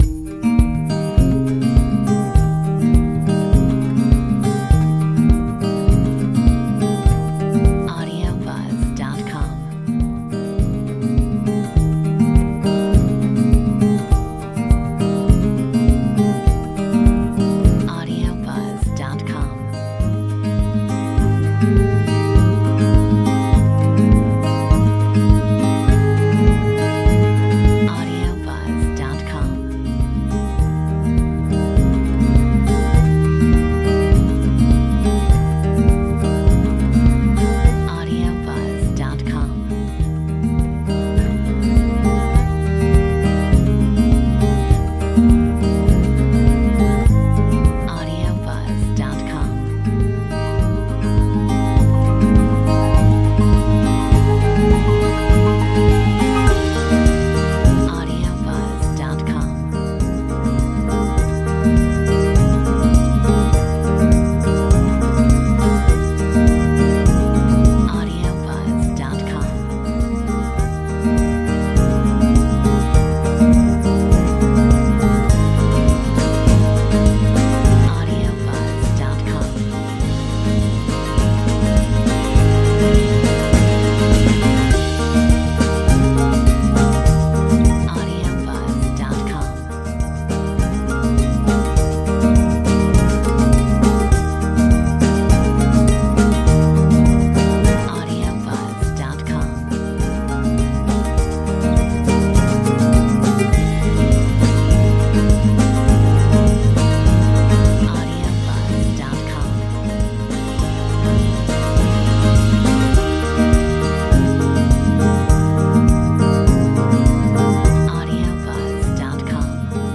Metronome 102